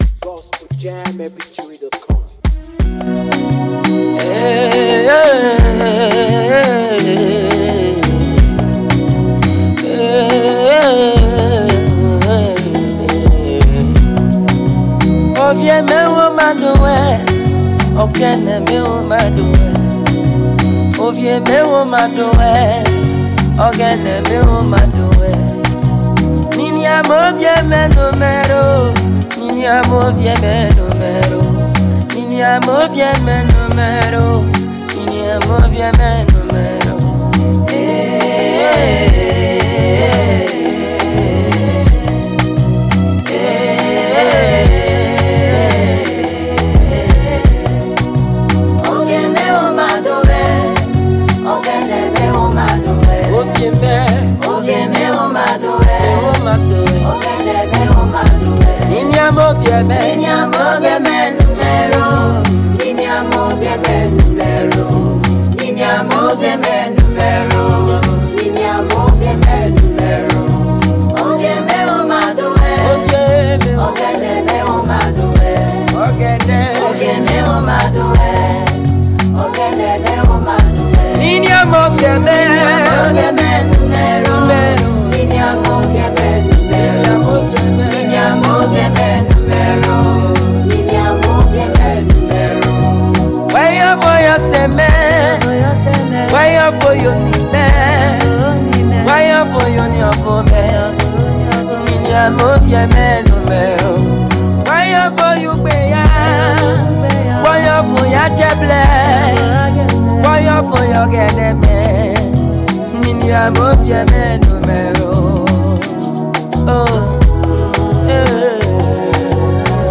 This a tribal song (Urhobo)